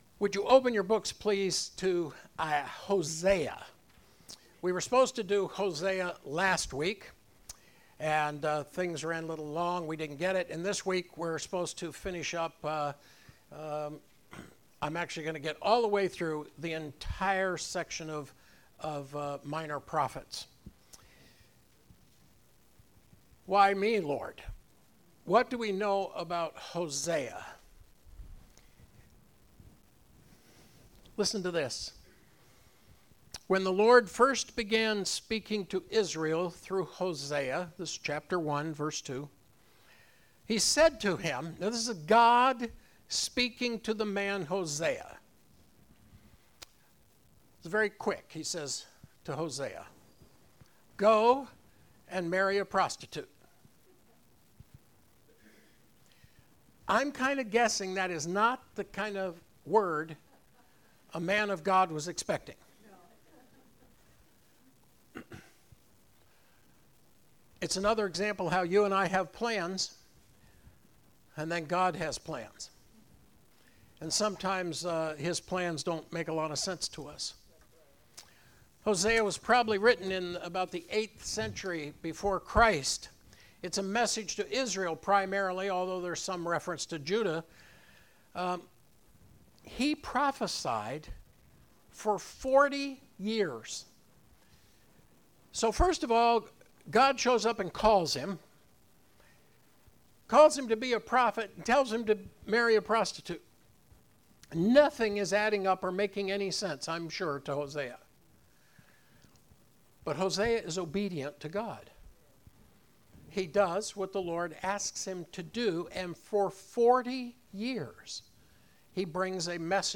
Sunday Morning Message for September 29, 2019